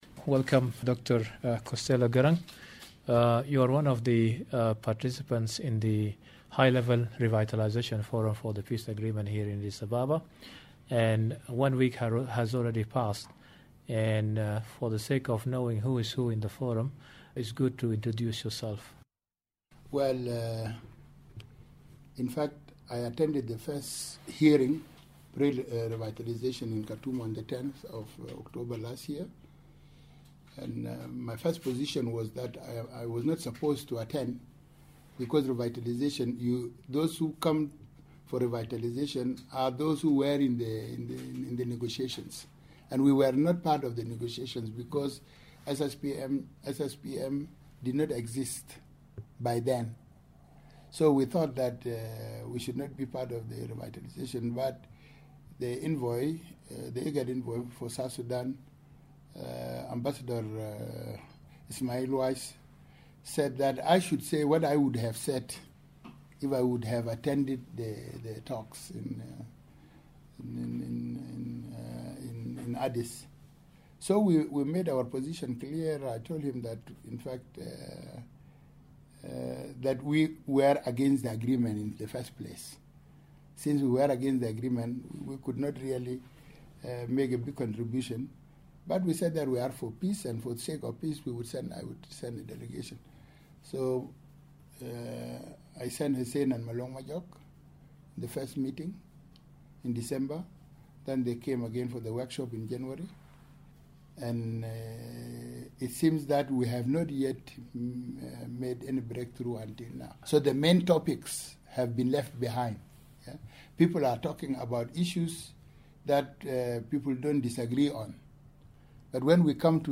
The price of peace: An interview with a South Sudanese SPLA veteran